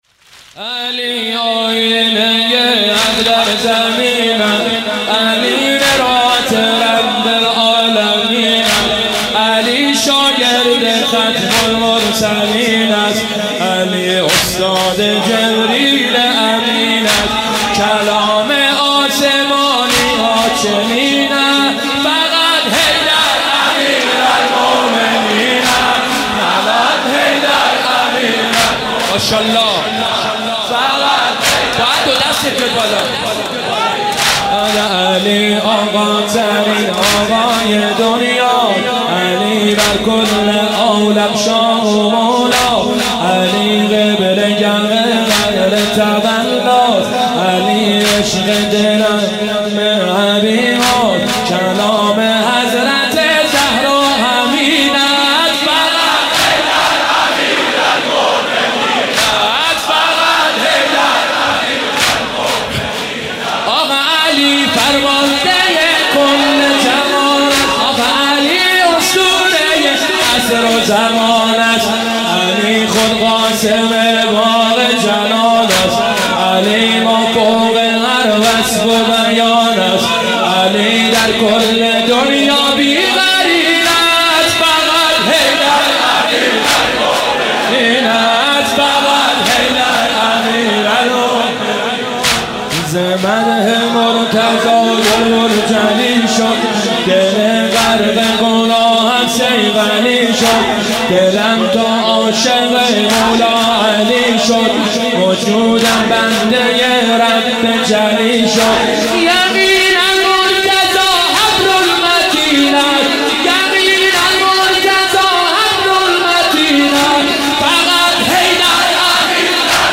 مناسبت : شب بیست و یکم رمضان - شب قدر دوم